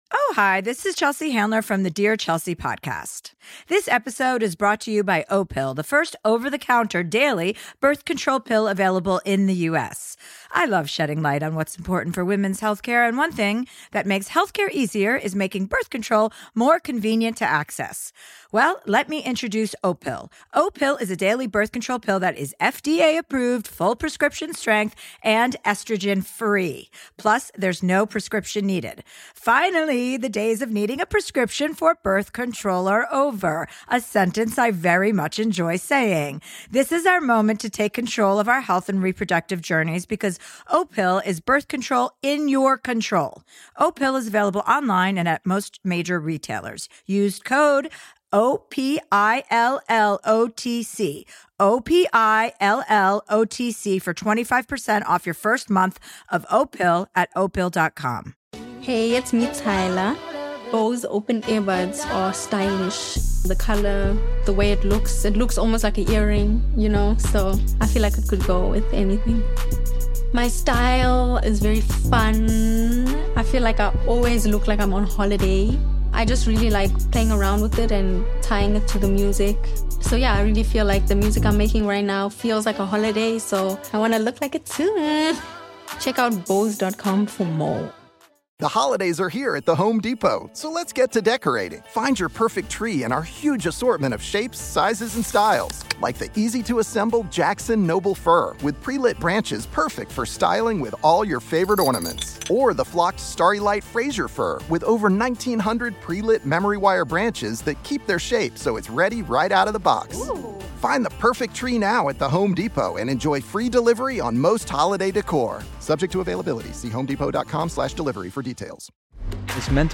On this episode of Our American Stories, Burt Young is an immediately recognizable character actor whose half-muttered voice and flinty gaze made him the perfect fit for "Rocky" as Sylvester Stallone's ill-mannered brother-in-law, Paulie. Here's Burt Young with his story.